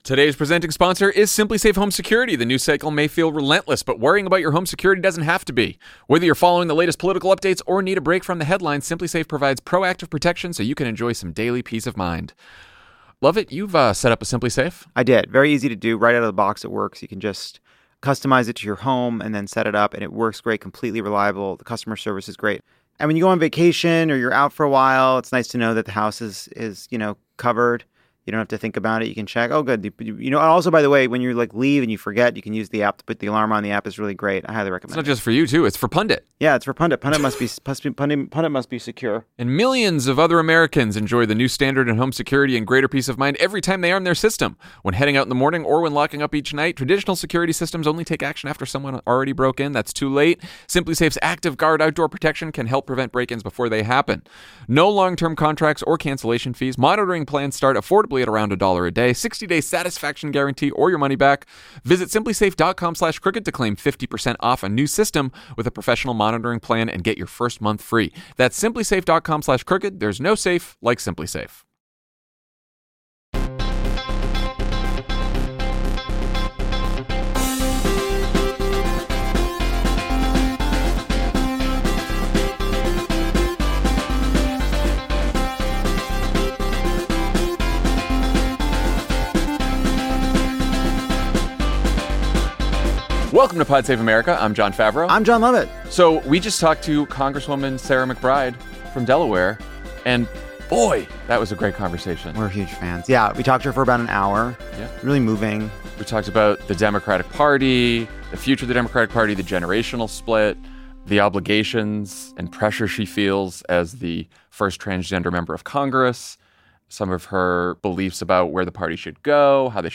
Now she's got a message for her fellow Democrats: politics only works when you win over people who disagree with you. McBride sits down with Jon and Lovett to discuss the literal and figurative dangers of being a main character, Democrats' purity complex, and whether the party has abandoned the only strategy for social change that actually gets results.